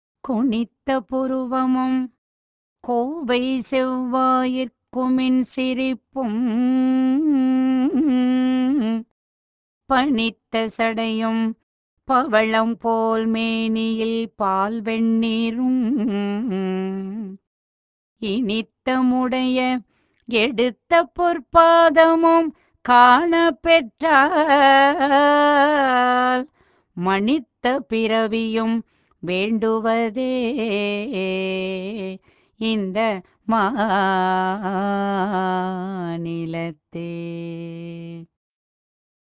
திருநாவுக்கரசர் இறைவனின் திருநடனத்தைப் பற்றிப் பாடுவது கேளுங்கள்!